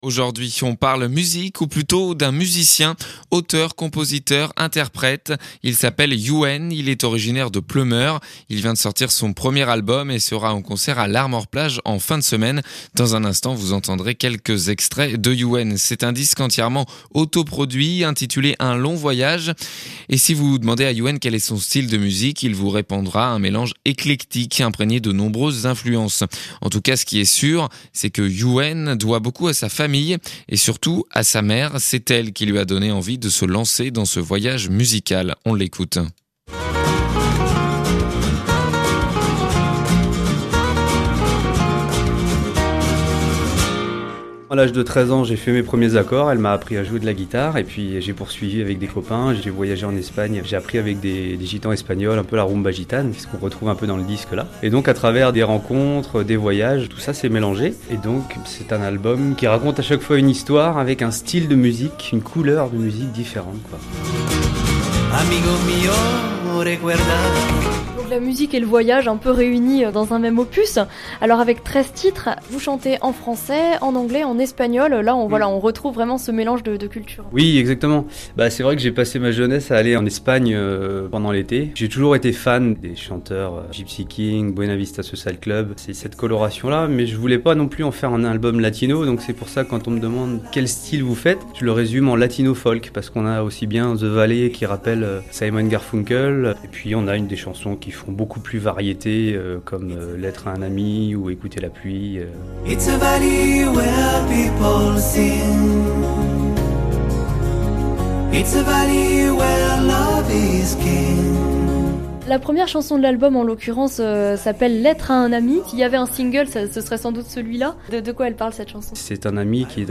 Interview du 14/02/2014